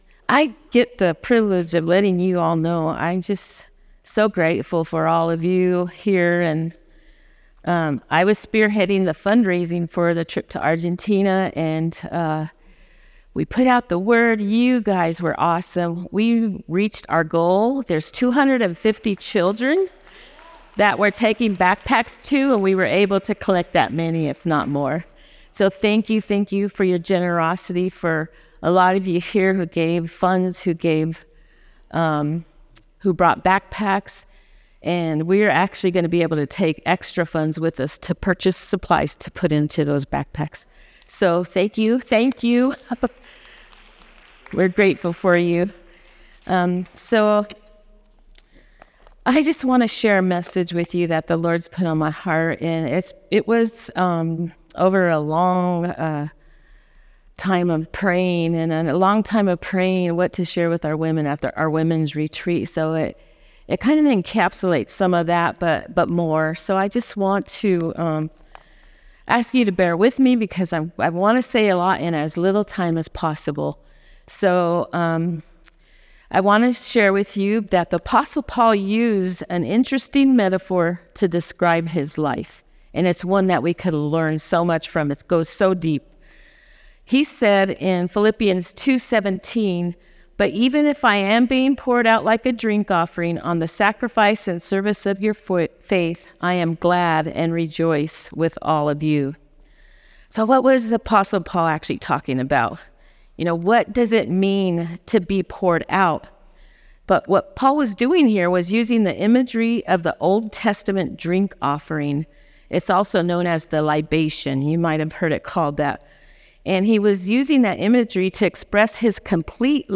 Replay of the weekend services